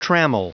Prononciation du mot trammel en anglais (fichier audio)
Prononciation du mot : trammel